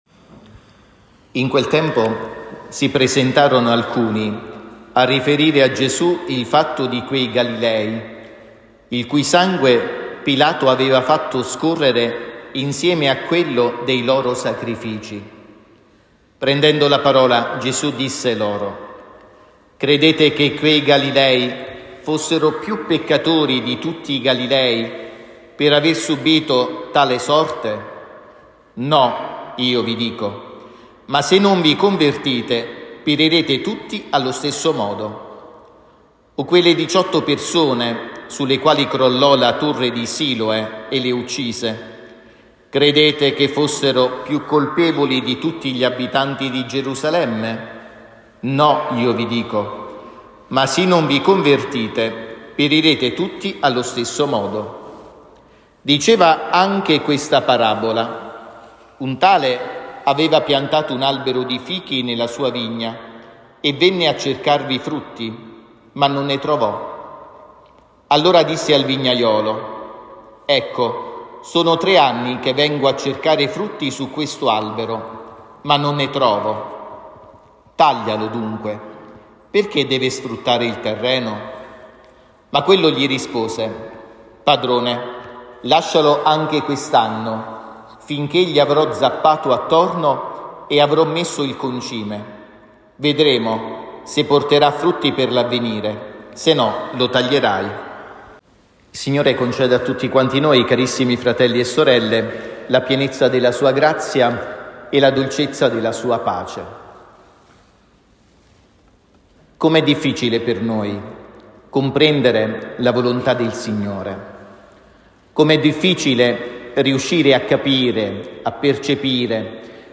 Omelia
Santuario Maria SS. Incoronata Montoro.